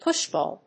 アクセント・音節púsh・bàll